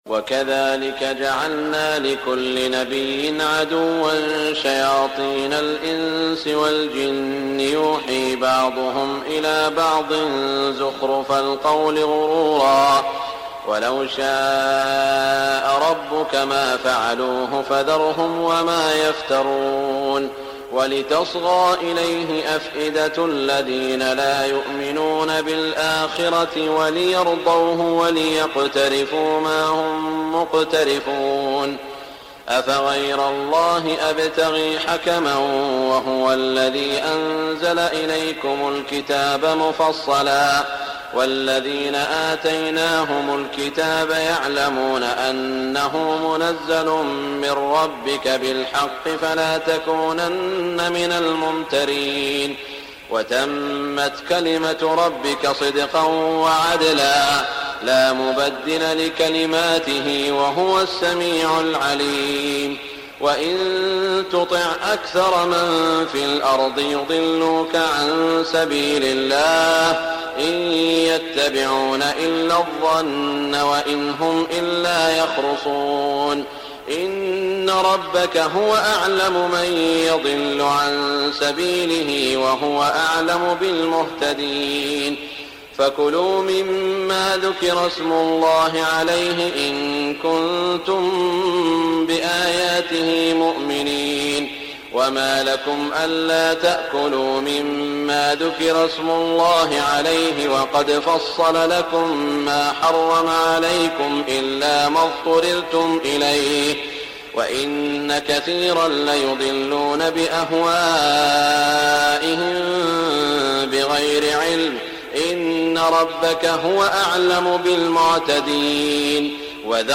تهجد ليلة 28 رمضان 1418هـ من سورتي الأنعام (112-165) و الأعراف (1-30) Tahajjud 28 st night Ramadan 1418H from Surah Al-An’aam and Al-A’raf > تراويح الحرم المكي عام 1418 🕋 > التراويح - تلاوات الحرمين